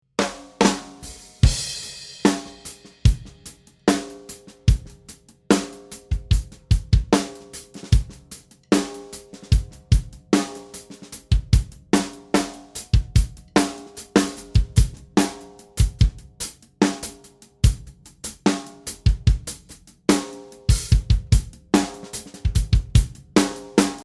Here you have a few audios with non-processed drums, to listen the 3 different options you can choose.
We recorded these tracks with different tunings on the snare, but with the same drum kit: a Yamaha Hybrid Maple, sizes 14×6, 10, 12, 14, 20 pretty low tension on toms and floor tom, and softly muffled bass drum.